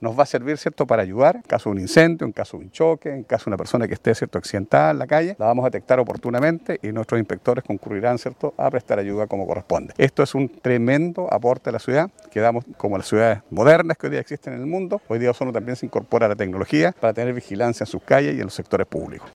Al respecto, el alcalde de la ciudad, Jaime Bertín, dijo que servirá en caso de incendios, colisión vehiculares y accidentes, que proporciona plusvalía de una ciudad moderna.